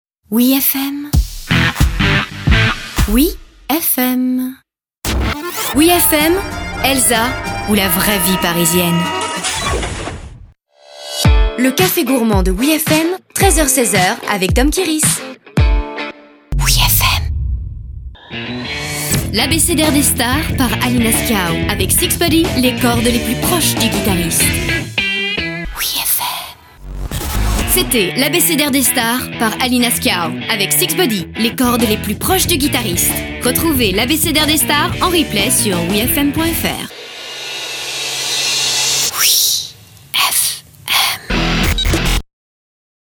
Voix off
Voix Habillage OUIFM